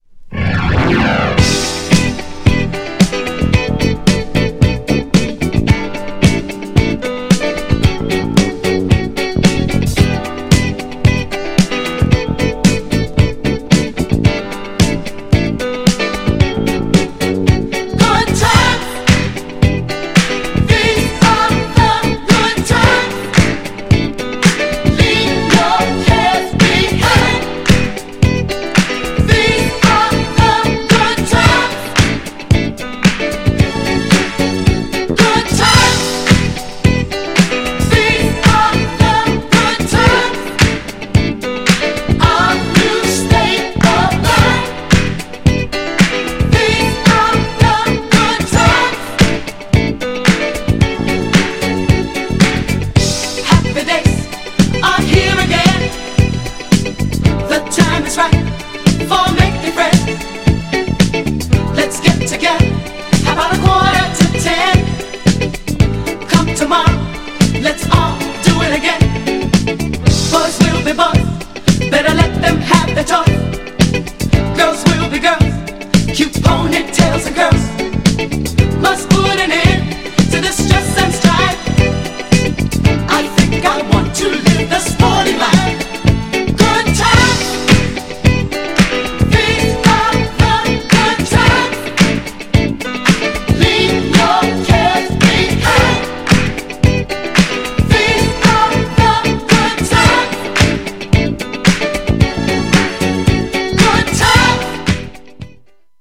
GENRE House
BPM 126〜130BPM